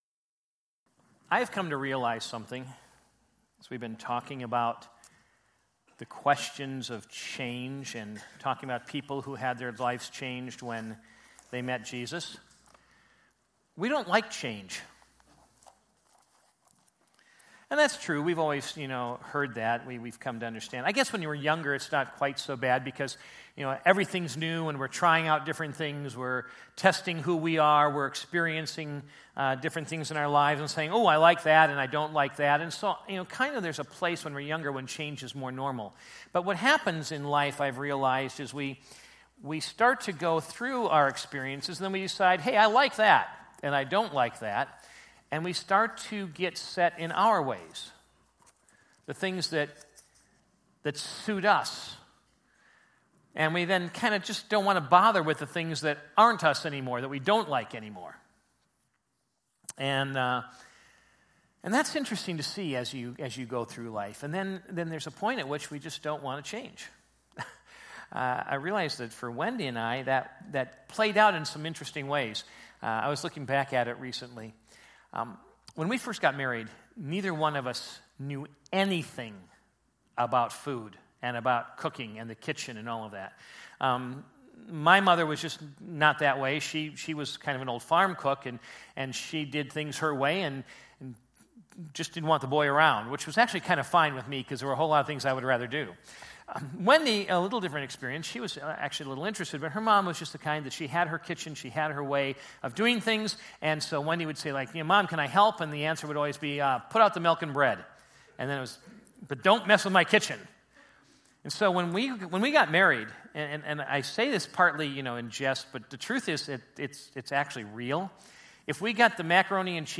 2017 Categories Sunday Morning Message Download Audio John 4 Previous Back Next